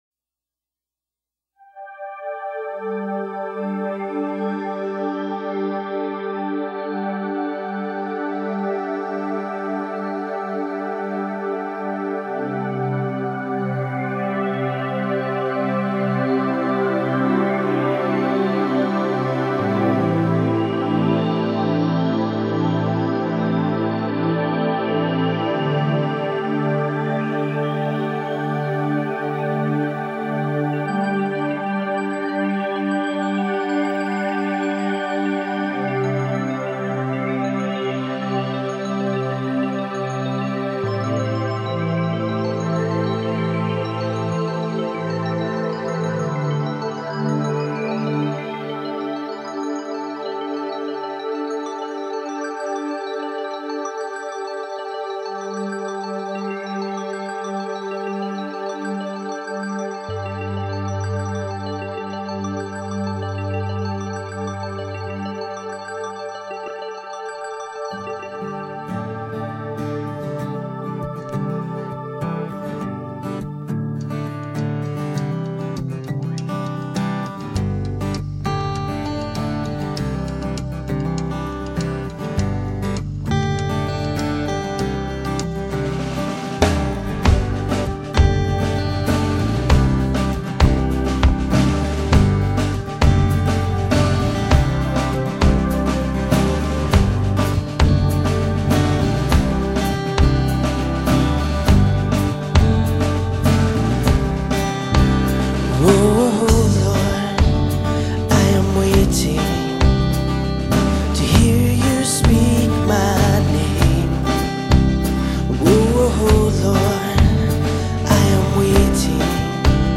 You Are Good – Intercession